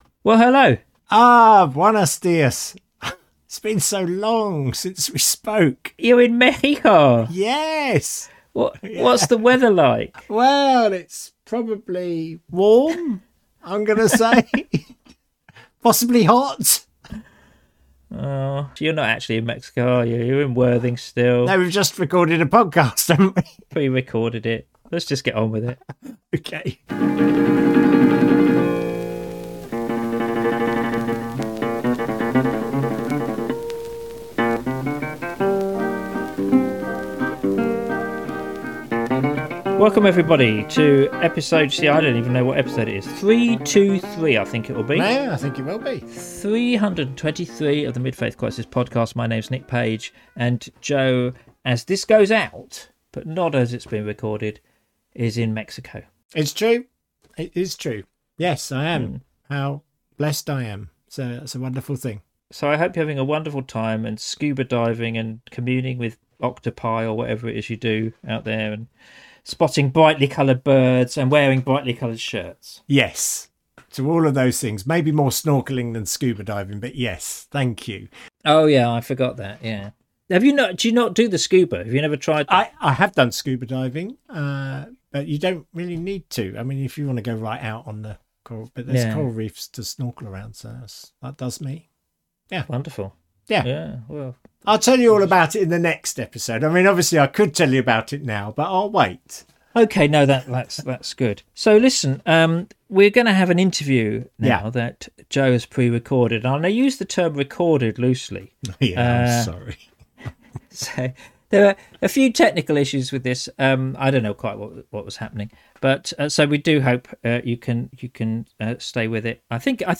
Episode 323: An interview